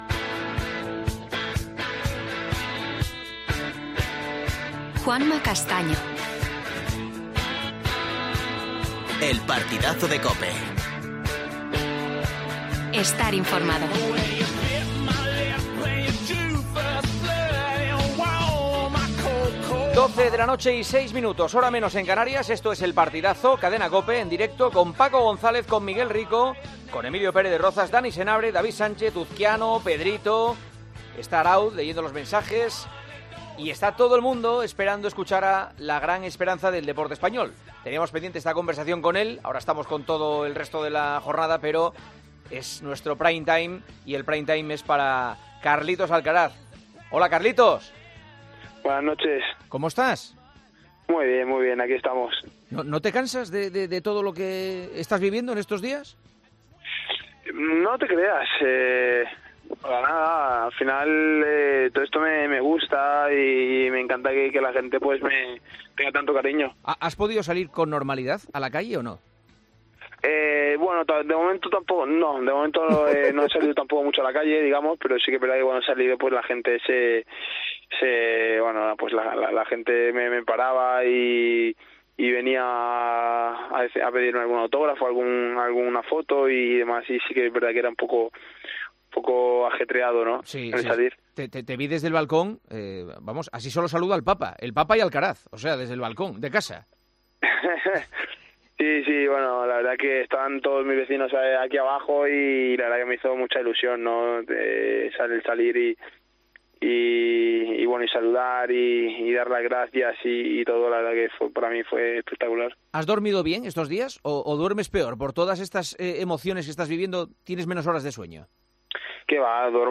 Juanma Castaño entrevista al reciente ganador del Mutua Madrid Open y tenista del momento, llamado a ser el sucesor de Rafa Nadal.